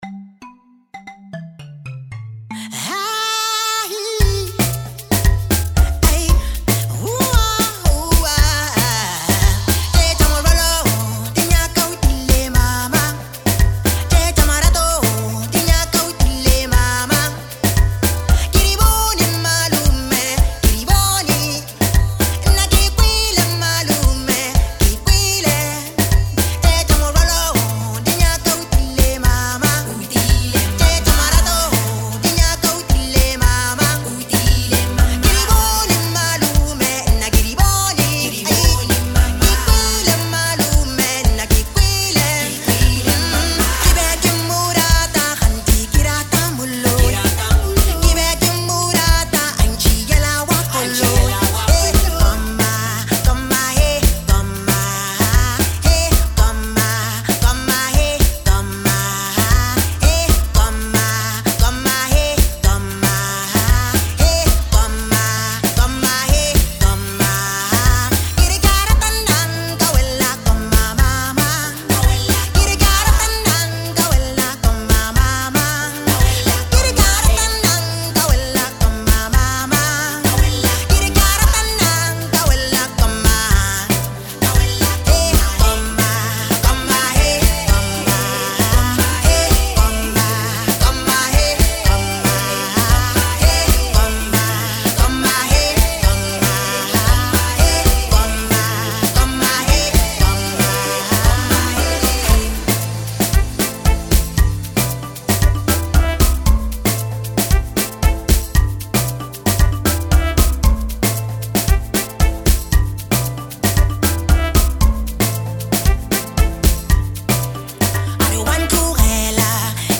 a heartfelt and emotional new single
With soulful vocals and a powerful instrumental backdrop